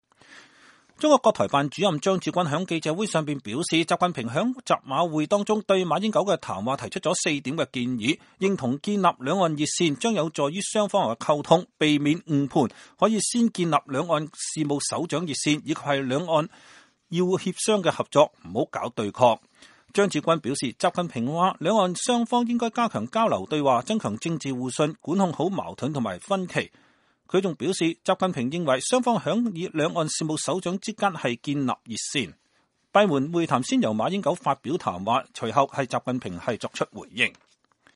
中國國台辦主任張志軍在記者會上表示，習近平在習馬會中對馬英九的談話提出四點建議，認同建立兩岸熱線，將有助於雙方溝通、避免誤判，可先建立兩岸事務首長熱線，以及希望兩岸要協商合作，不要對抗。